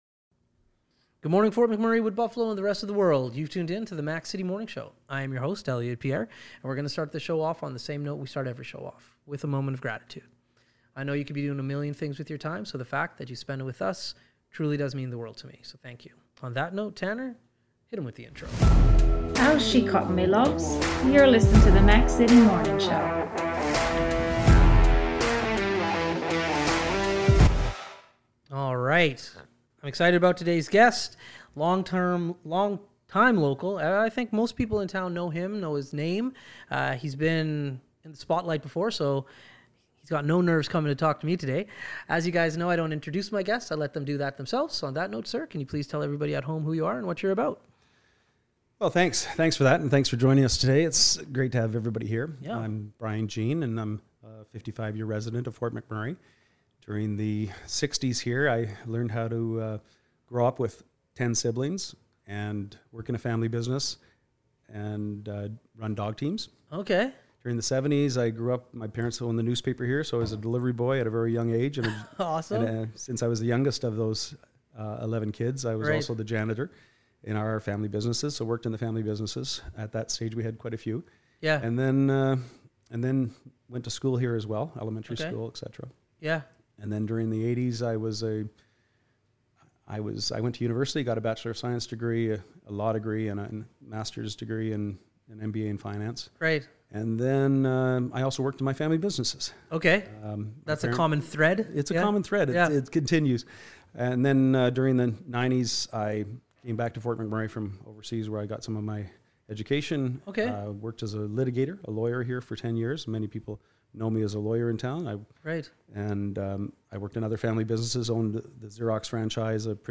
Brian Jean, a local politician stops by for a chat!